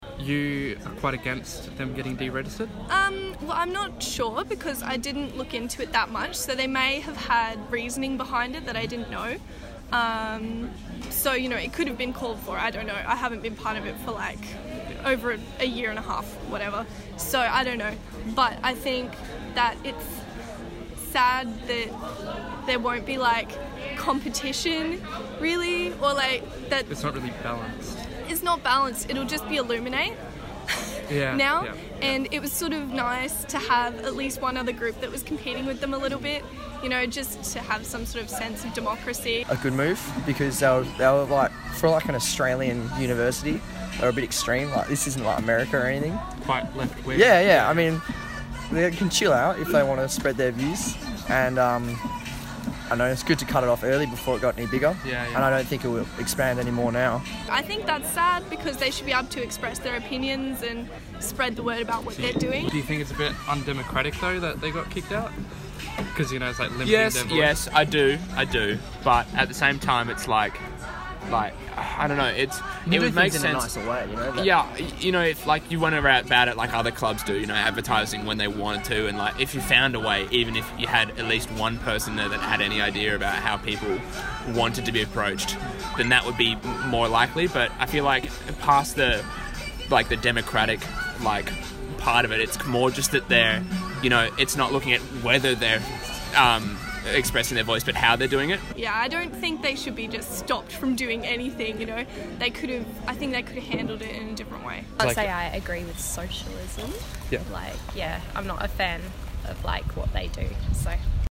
Vox-pops-on-2018-Guild-AGM.mp3